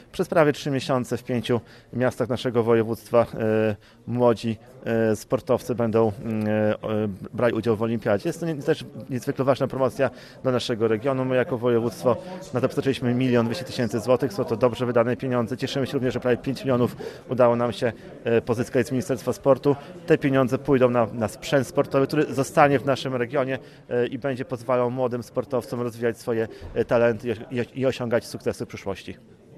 Obecny na ceremonii otwarcia Łukasz Prokorym, Marszałek Województwa Podlaskiego, zaznaczył, że takie zawody to duży krok w kierunku promocji województwa.